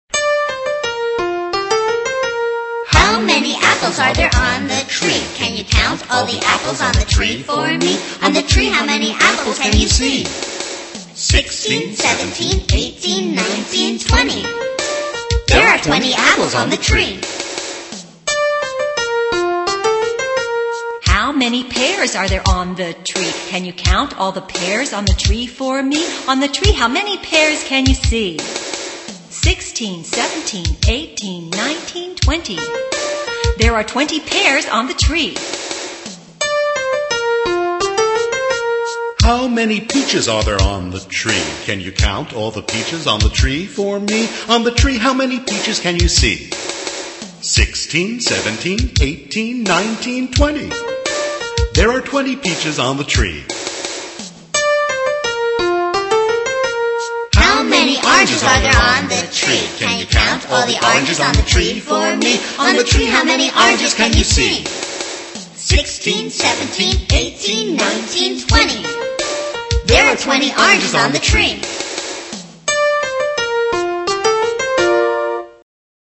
在线英语听力室英语儿歌274首 第75期:How many apples的听力文件下载,收录了274首发音地道纯正，音乐节奏活泼动人的英文儿歌，从小培养对英语的爱好，为以后萌娃学习更多的英语知识，打下坚实的基础。